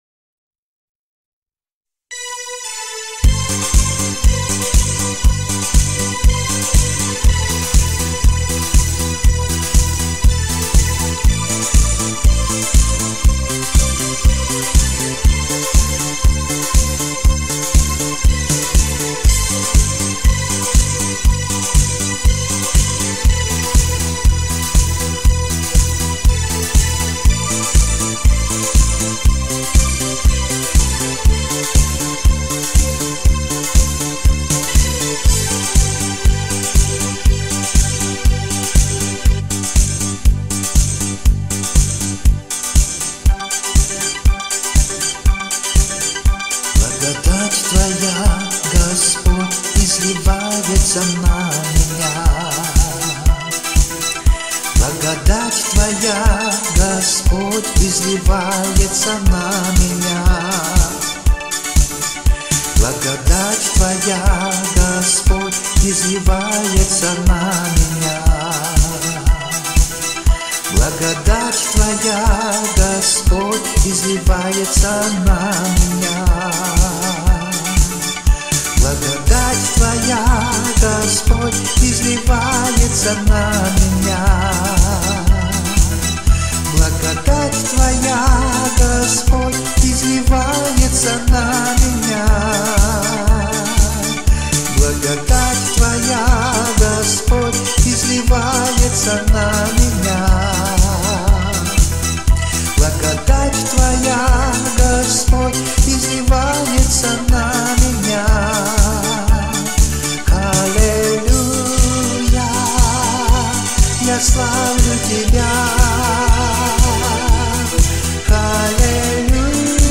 1087 просмотров 615 прослушиваний 53 скачивания BPM: 150